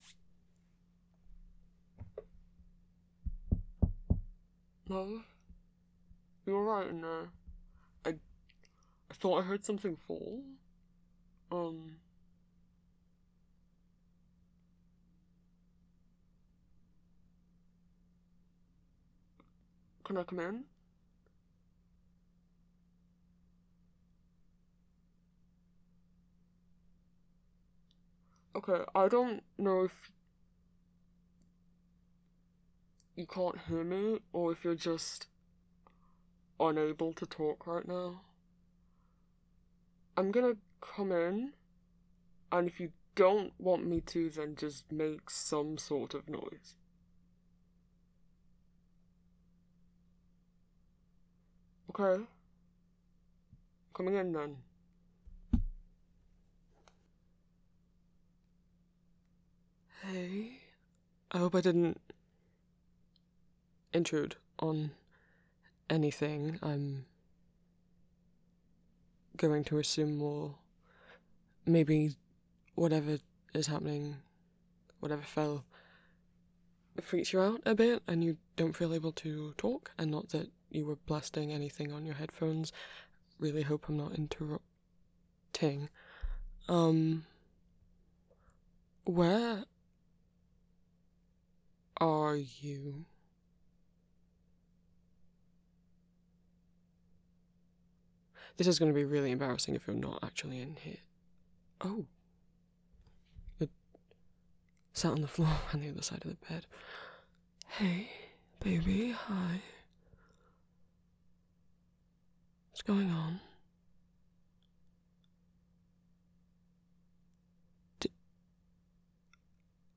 [M4A] [Panic attack comfort] [Comfort] [Pet names]